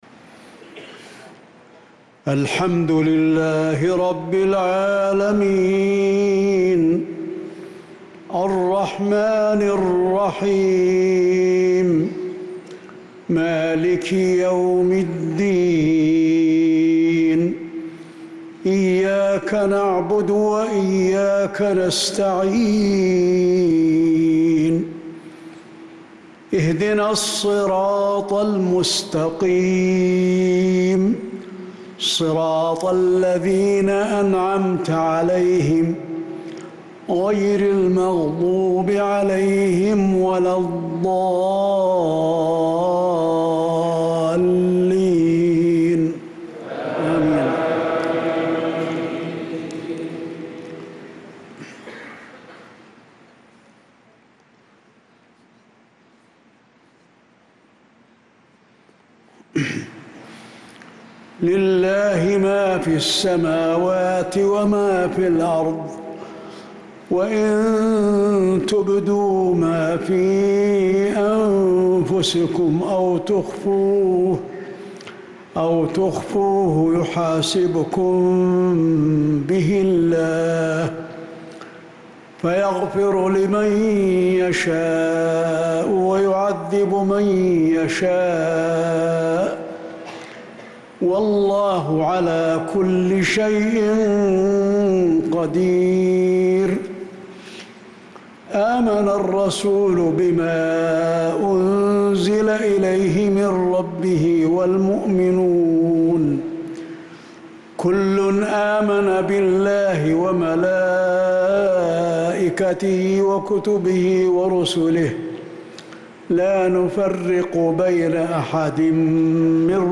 صلاة المغرب للقارئ علي الحذيفي 10 ربيع الآخر 1446 هـ